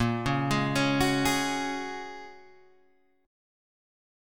A# Minor 7th Sharp 5th